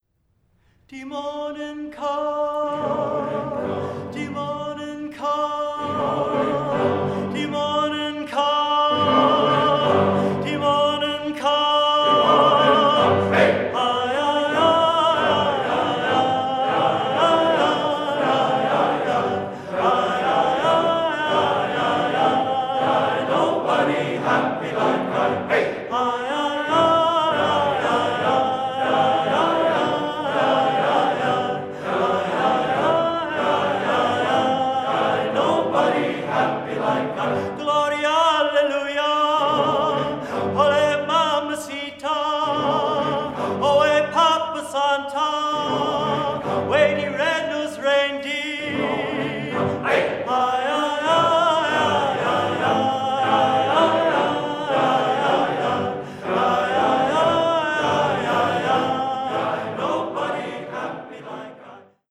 American Spirituals — The Apollo Club
The Morning Come The Apollo Chorus